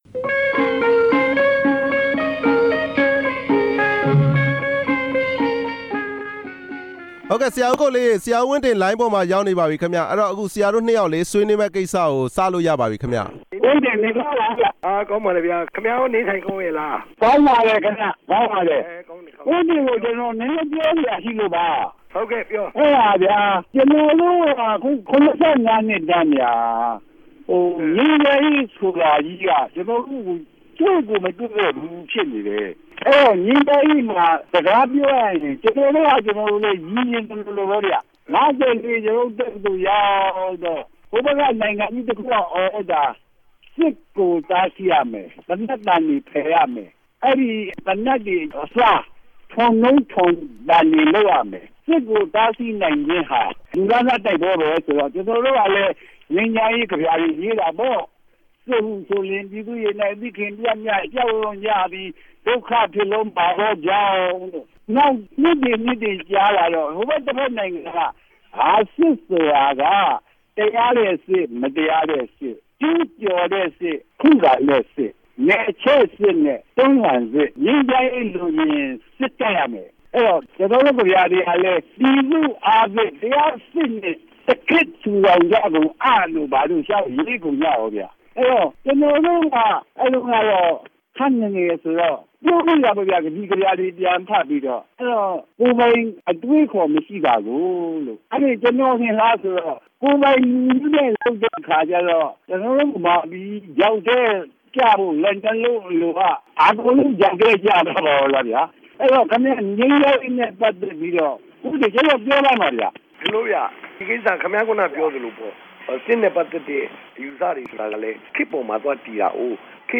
ဦးဝင်းတင်နှင့် စကားပြောခြင်း